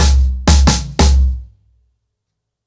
Wireless-90BPM.23.wav